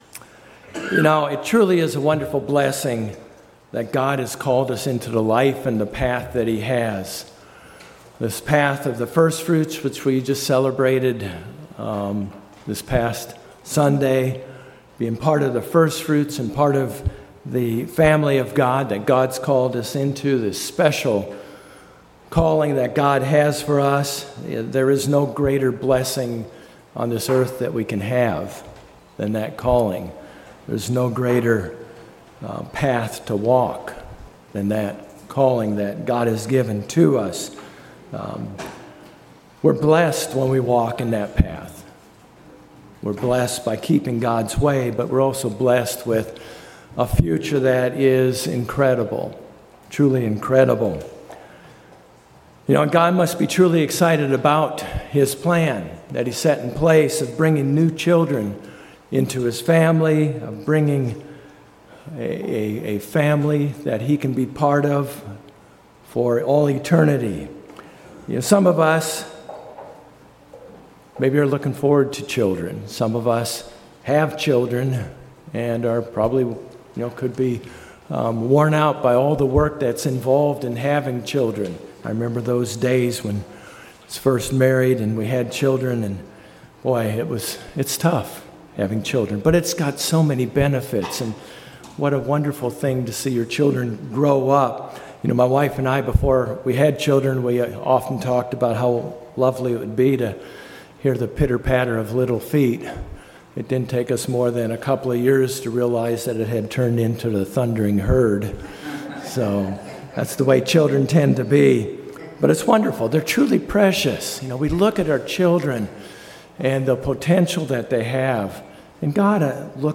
God is preparing us now to become the bride for Jesus Christ. In this sermon we look at various ways that it is happening.